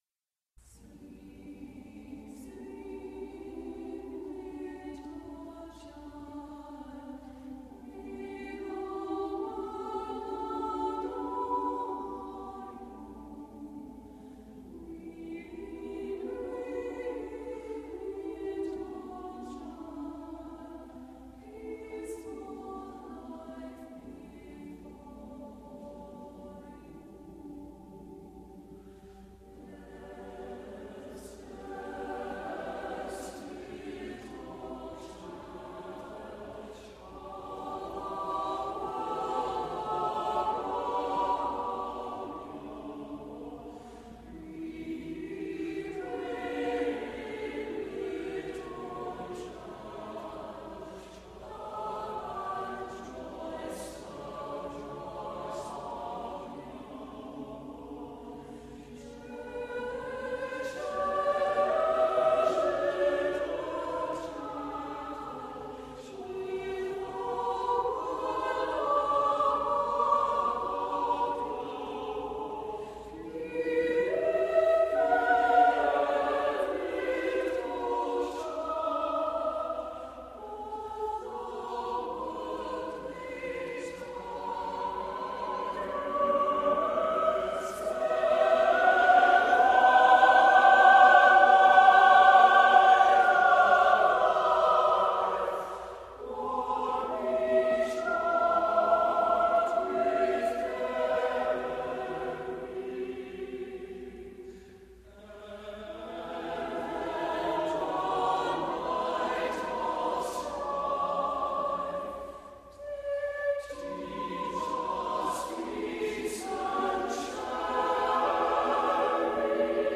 Voicing: SATB
Instrumentation: a cappella
Washington, DC